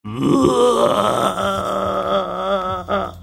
Pierścień LED oświetla oczy i usta, a głośnik schowany wewnątrz wydobywa przerażający dźwięk, gdy tylko ktoś podejdzie zbyt blisko.